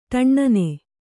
♪ ṭaṇṇane